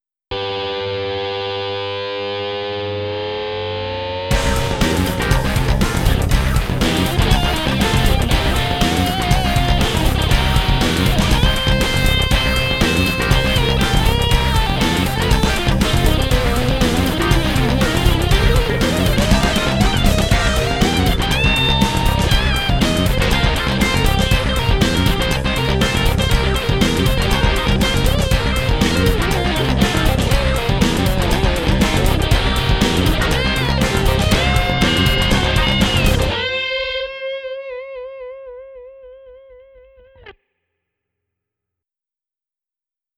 Μουσική Παράστασης